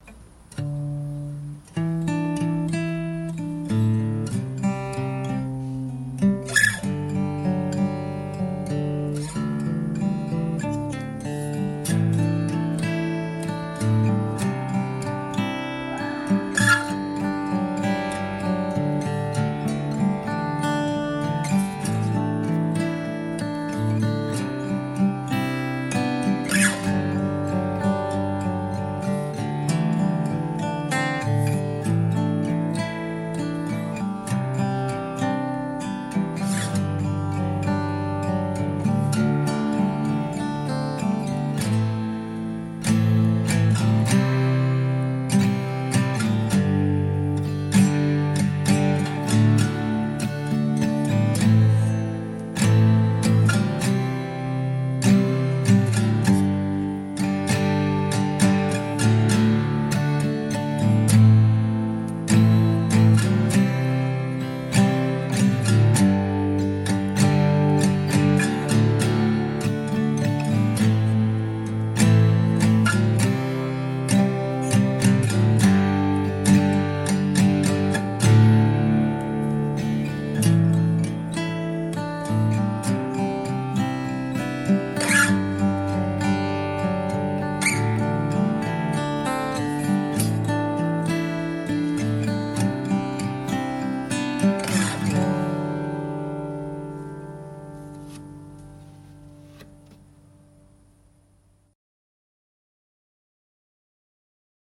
Già che ci sono, dumpo qualcuna delle mie mille mila registrazioni, tutte fatte random col telefonino e mixate al volo senza pretesa di precisione con la sincronia audio. Ho fatto quello che potevo, ma se non altro si sente il tocco diverso in ogni file.